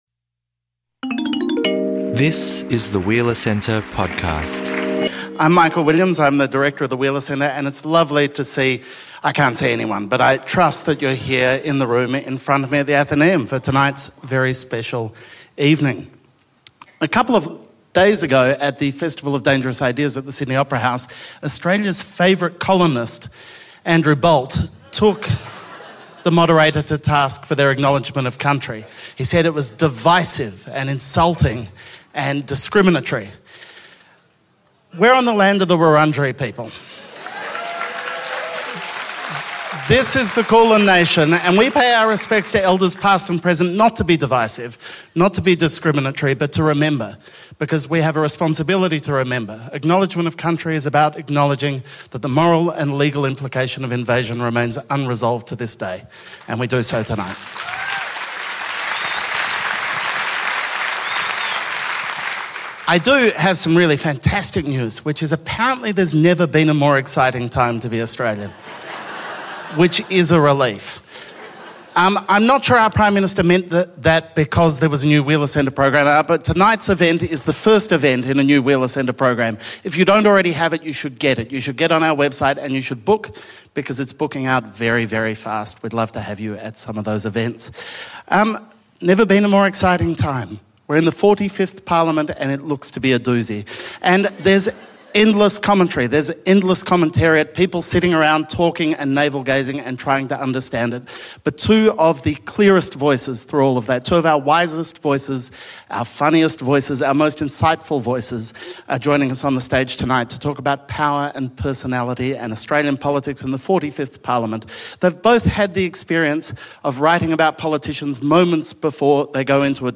In this discussion at the Athenaeum Theatre, two of the sharpest minds in Australian journalism – Annabel Crabb and David Marr – discuss two of the most powerful men in Australia: Turnbull and Shorten.
Listen as Marr and Crabb share a lively, illuminating conversation about political personalities and the will to power.